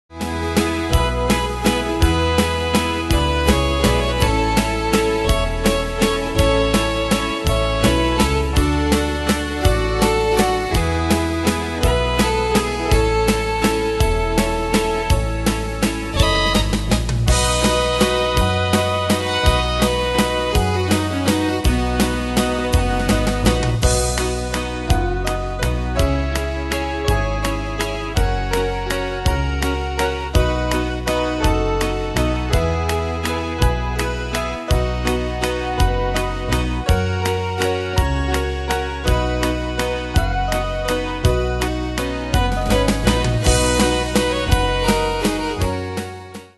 Style: Country Ane/Year: 1990 Tempo: 165 Durée/Time: 3.31
Danse/Dance: Valse/Waltz Cat Id.
Pro Backing Tracks